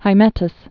(hī-mĕtəs)